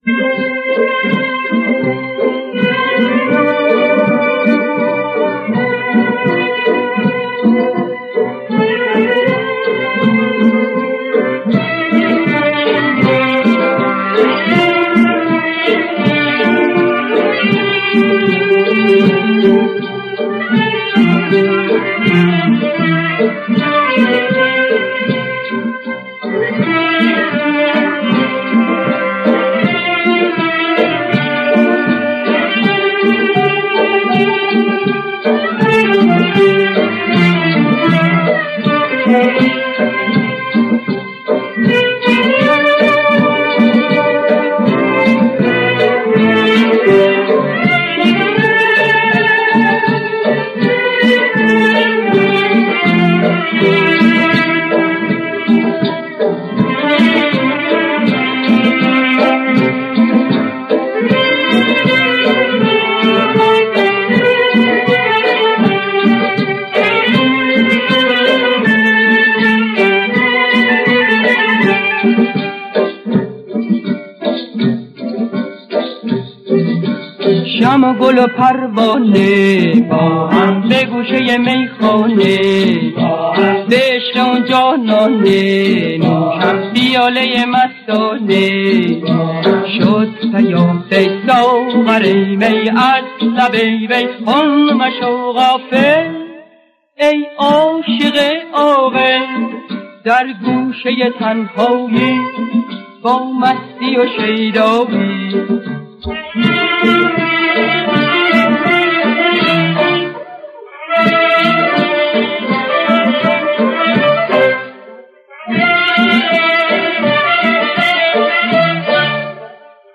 در مقام ماهور